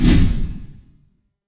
Cinematic Swoosh
A dramatic cinematic swoosh with deep bass movement and bright high-frequency trail
cinematic-swoosh.mp3